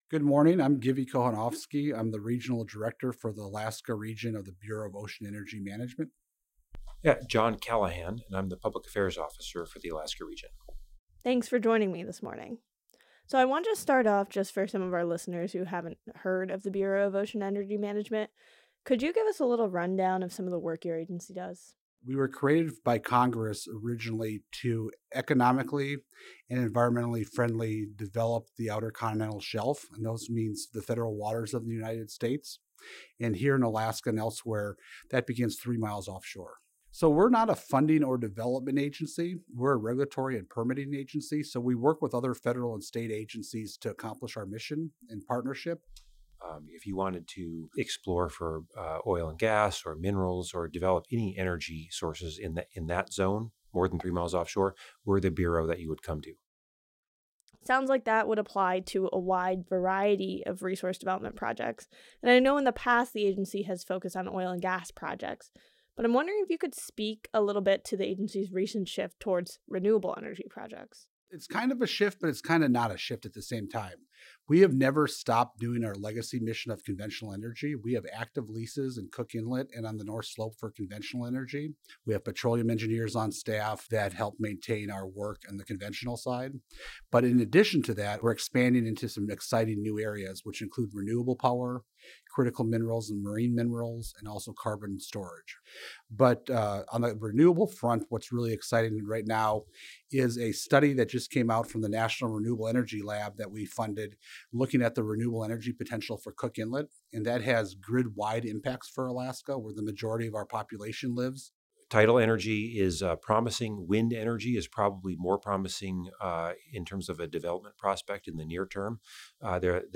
This interview has been edited for length and clarity.